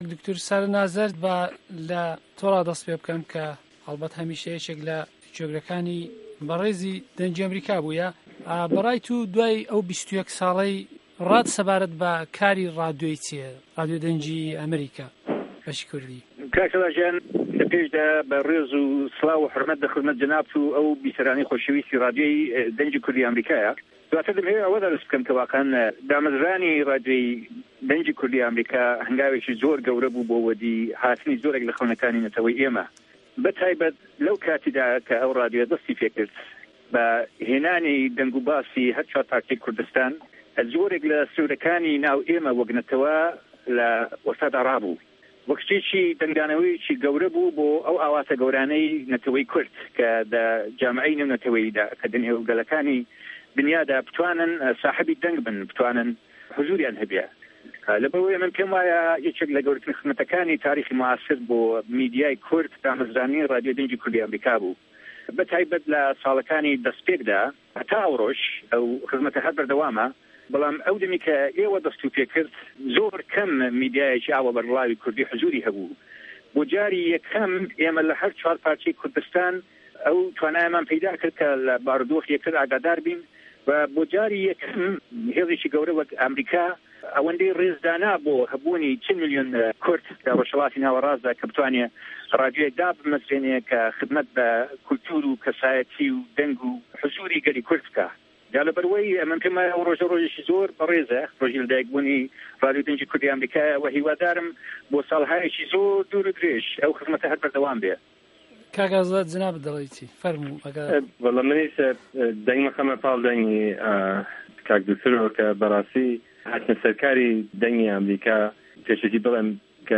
مێزگرد به‌ بۆنه‌ی دامه‌زراندنی ڕادیۆ